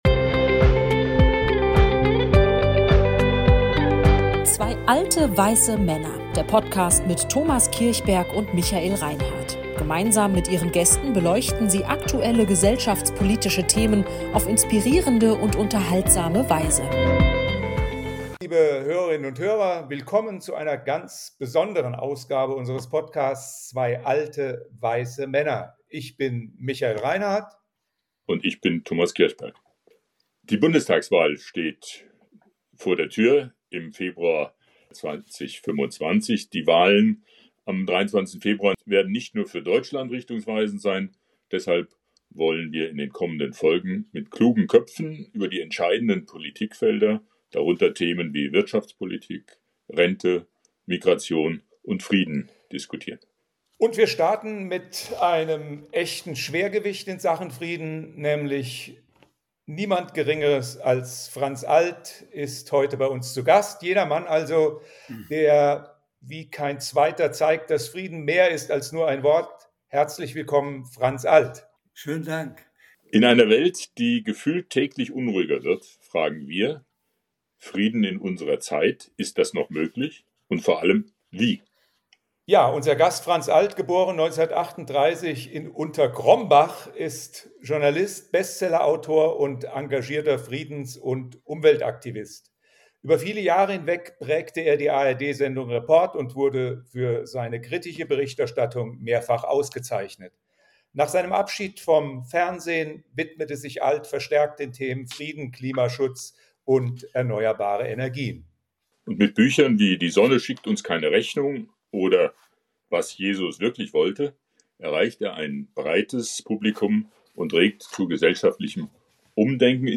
Ihr Gesprächspartner ist der bekannten Friedens- und Umweltaktivisten Franz Alt.